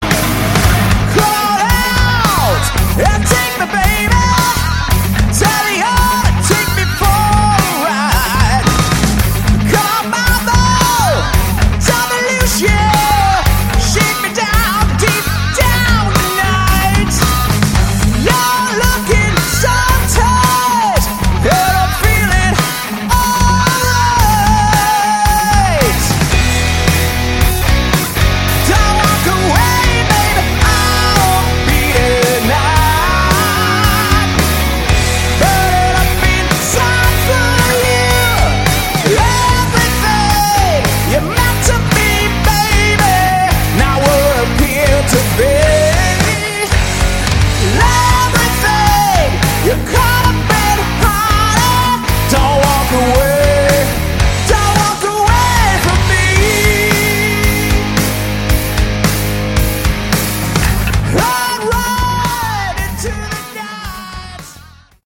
Category: Hard Rock
vocals
guitar
bass, vocals
drums, vocals